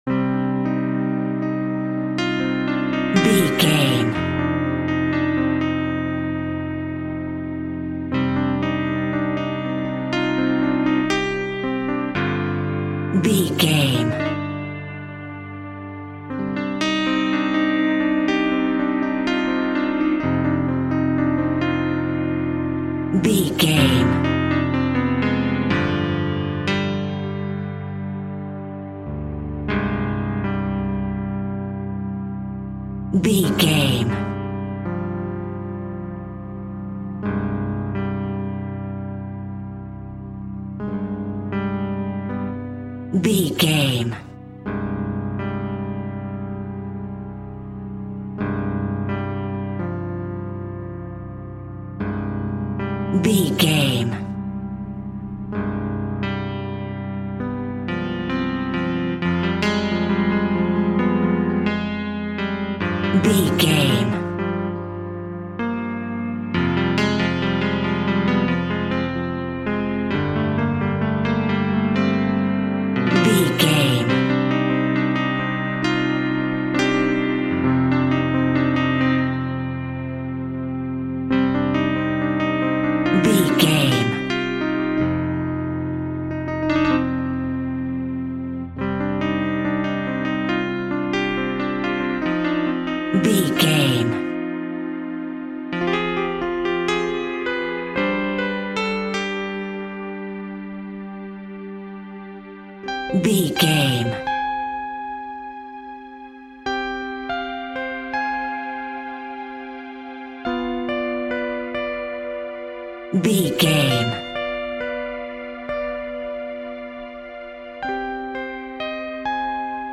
In-crescendo
Aeolian/Minor
scary
ominous
haunting
eerie
strings
synth
pads